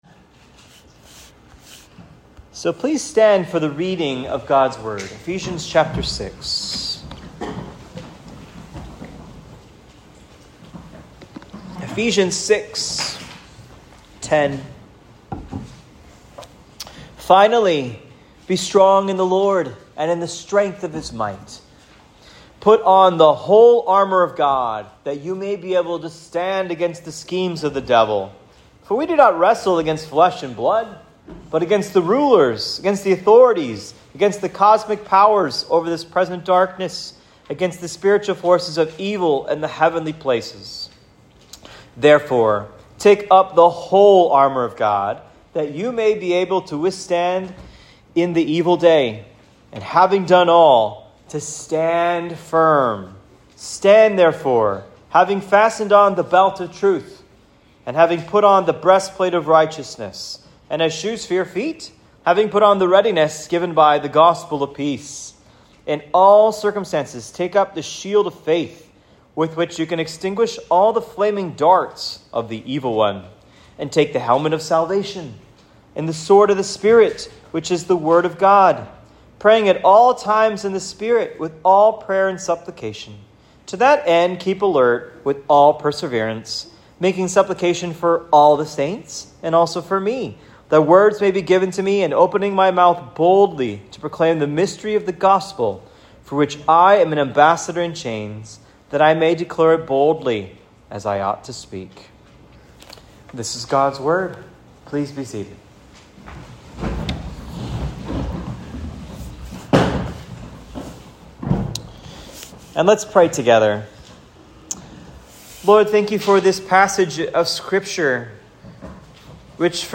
Armor of God Sermon (Eph 6:10-20) Part 2